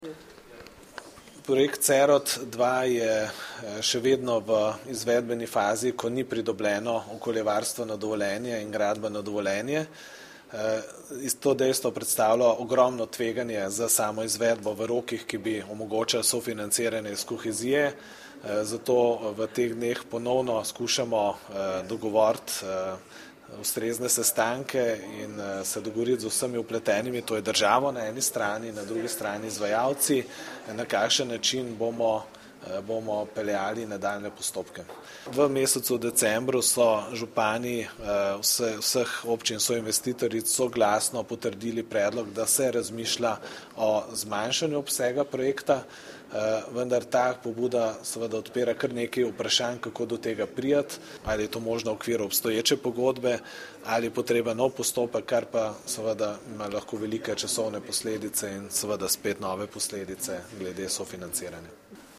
Novo mesto, 20. januar 2015 - Župan Mestne občine Novo mesto Gregor Macedoni je na današnji dopoldanski novinarski konferenci predstavil delo Mestne občine Novo mesto v zadnjem mesecu ter izpostavil ključne korake, ki jih je mestna občina storila pri posameznih aktualnih projektih.
Župan Gregor Macedoni o plačevanju najemnine za prostore RIC